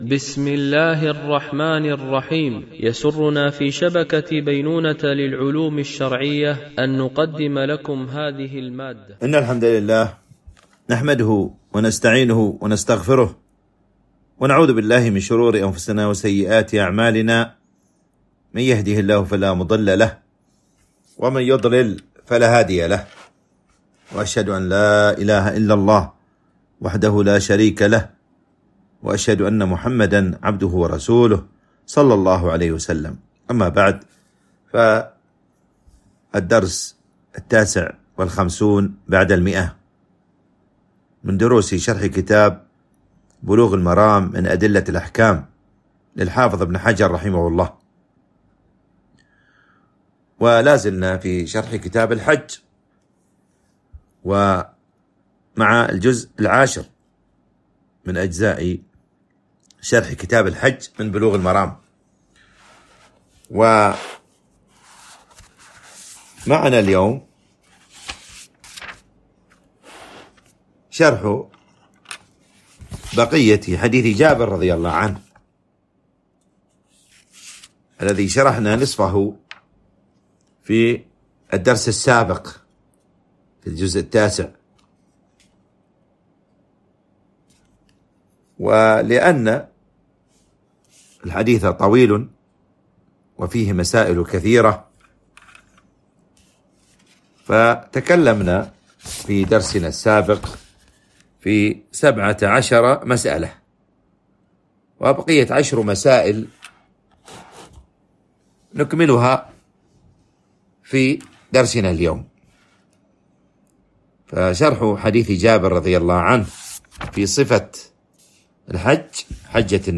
الدرس 159
MP3 Mono 44kHz 64Kbps (VBR)